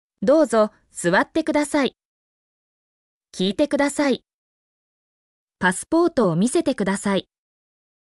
mp3-output-ttsfreedotcom-16_TsZtfXzF.mp3